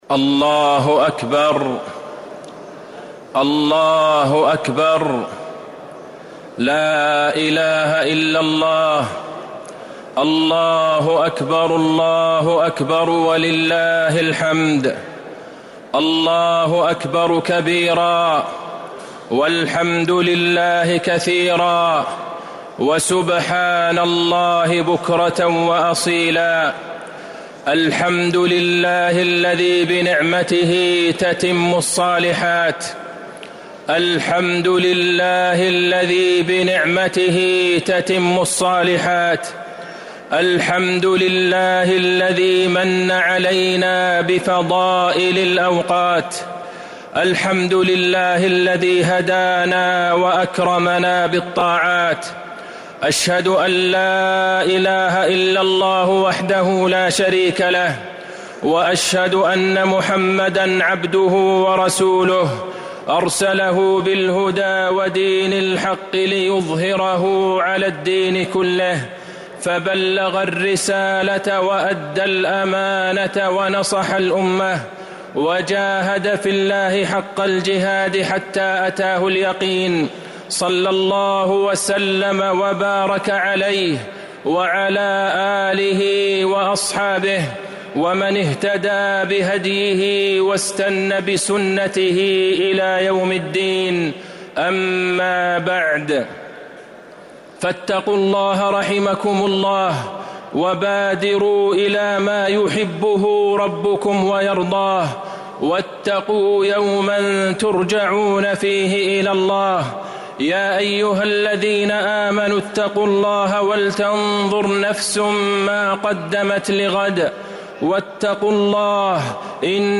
المدينة: خطبة عيد الفطر لعام 1446هـ - عبد الله بن عبد الرحمن البعيجان - طريق الإسلام
المدينة: خطبة عيد الفطر لعام 1446هـ - عبد الله بن عبد الرحمن البعيجان (صوت - جودة عالية